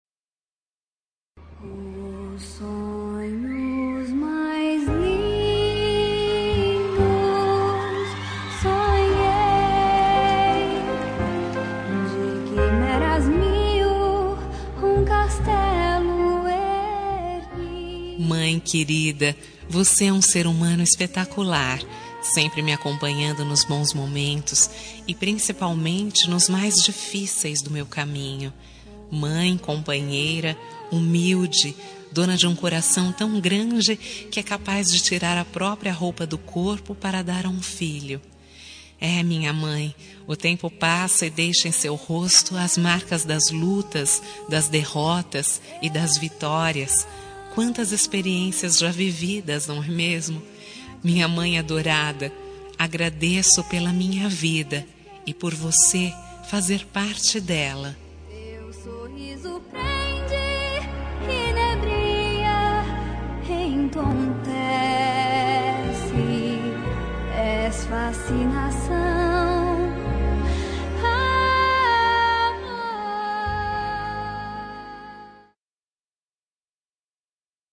Telemensagem de Agradecimento – Para Mãe – Voz Feminina – Cód:11
Agradecimento a Mãe - fem -2041.mp3